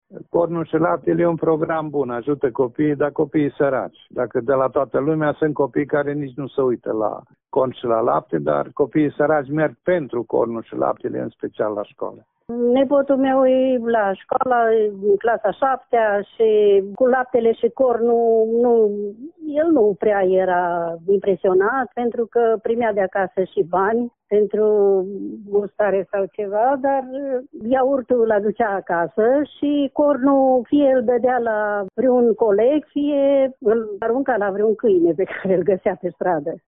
Părinții spun că aceste programe au succes, mai ales pentru copiii care provin din familii cu venituri reduse.